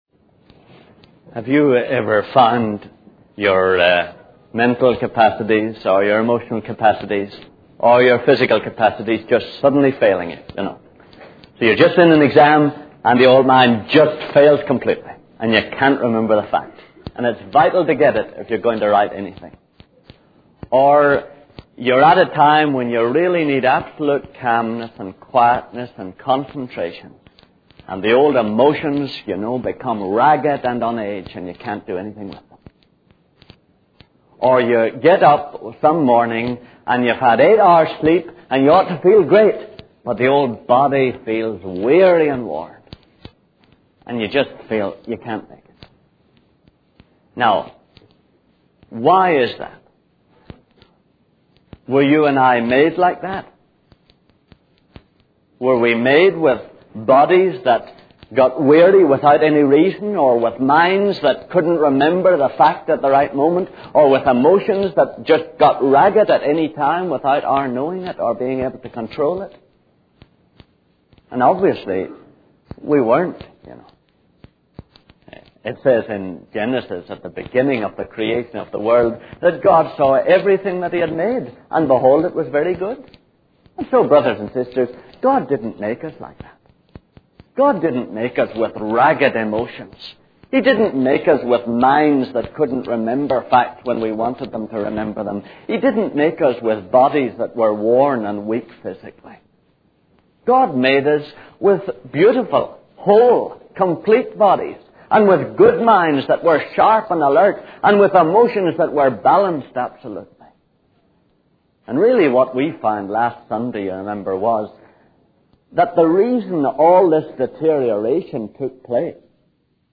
In this sermon, the preacher discusses the concept of death and how it is passed on from generation to generation. He explains that there are three types of death: physical death, eternal separation from God, and the second death of being cast into the lake of fire.